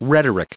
added pronounciation and merriam webster audio
656_rhetoric.ogg